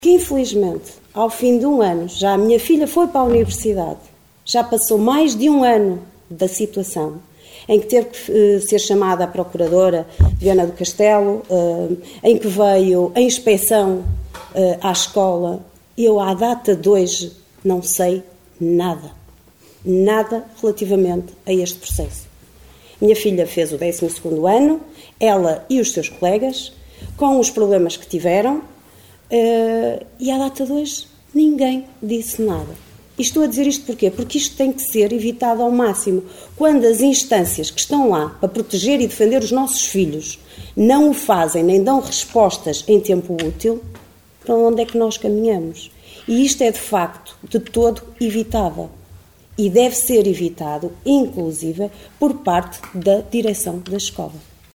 Liliana Silva, da Coligação O Concelho em Primeiro (OCP), acusou na última reunião do executivo a direção do Agrupamento de Escolas de Caminha de abafar os casos de bullying (e outros).